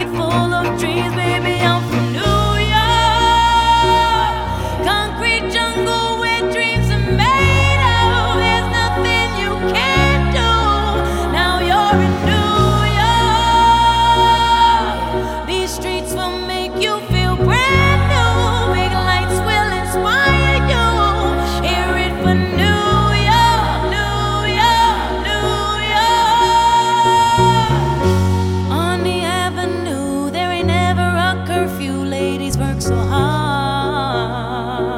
Жанр: Хип-Хоп / Рэп / Рок / R&B / Альтернатива / Соул